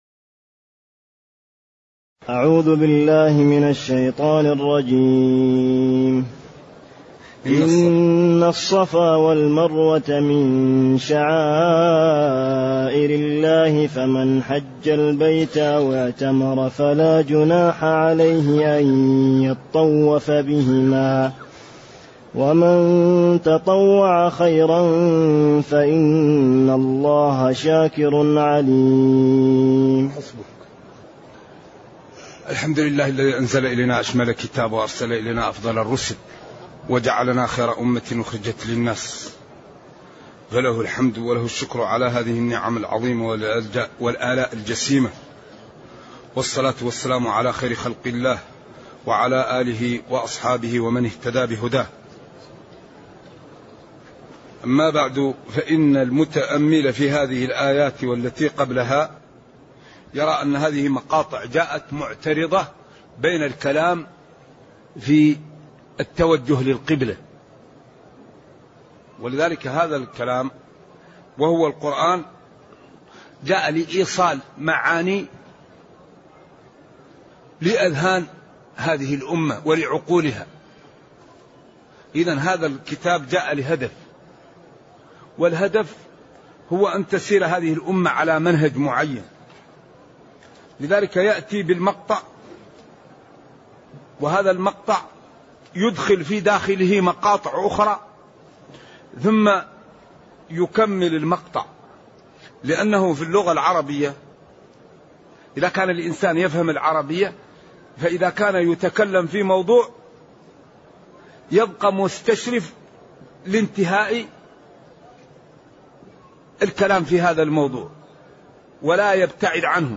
تاريخ النشر ١١ رجب ١٤٢٨ هـ المكان: المسجد النبوي الشيخ